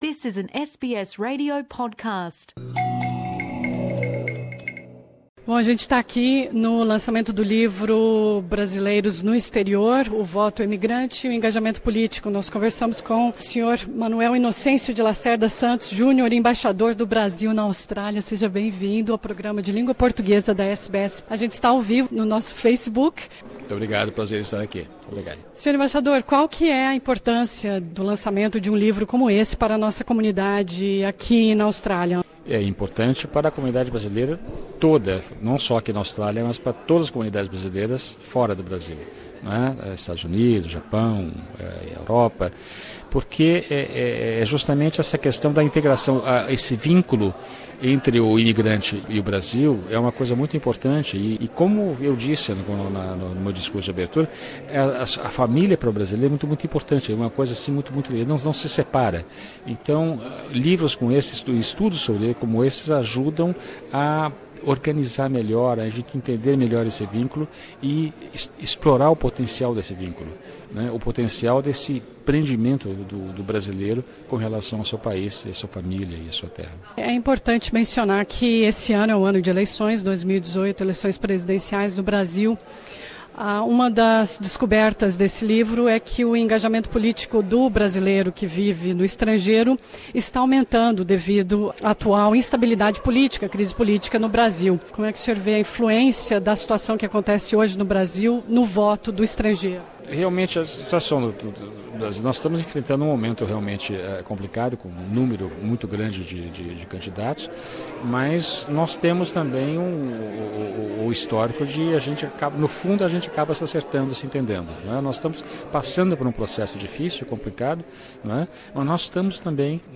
Ouça entrevista com o embaixador do Brasil na Austrália, senhor Manuel Innocencio de Lacerda Santos Jr. sobre conscientização política do brasileiro no exterior durante o evento de lançamento do livro 'Brasileiros no Exterior, Voto Emigrante e Engajamento Político’.